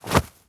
foley_cloth_light_fast_movement_10.wav